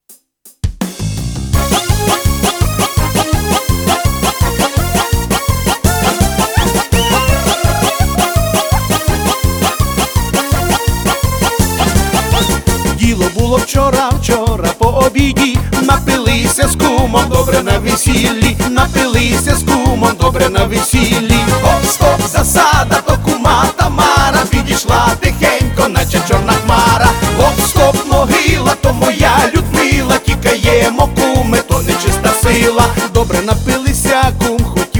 Жанр: Кантри / Украинские